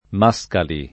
Mascali [ m #S kali ]